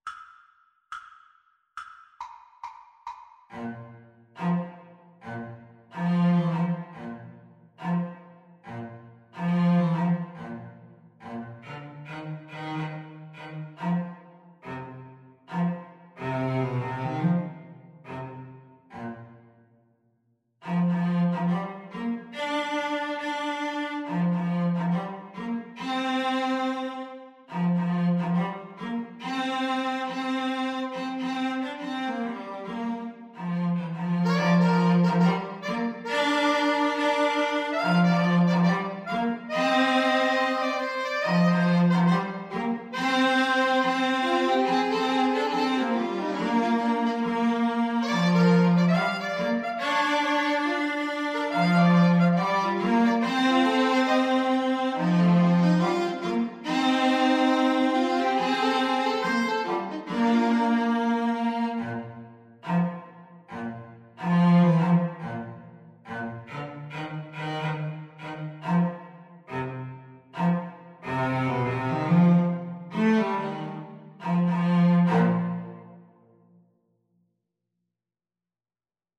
Alto SaxophoneCello
4/4 (View more 4/4 Music)
Quick and with a swing = c. 140